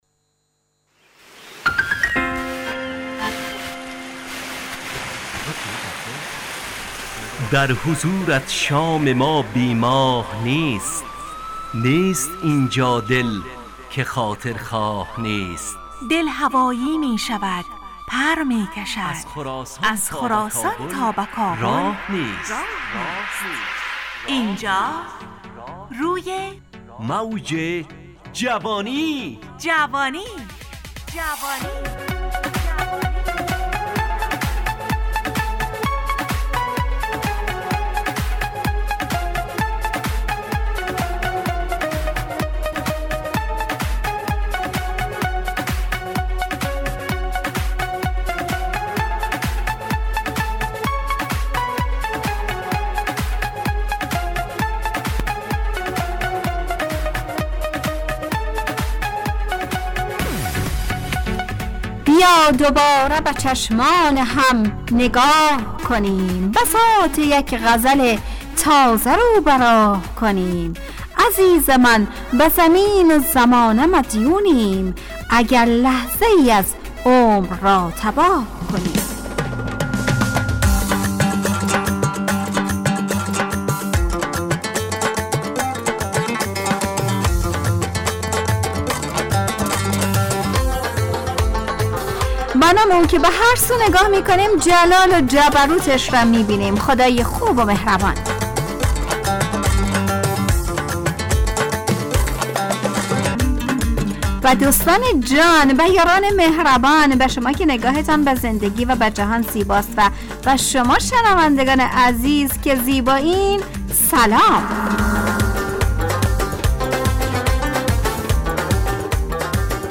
روی موج جوانی، برنامه شادو عصرانه رادیودری.